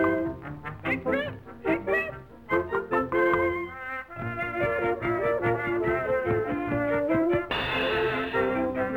Tap along to the beat. Each of your taps will be 10x apart.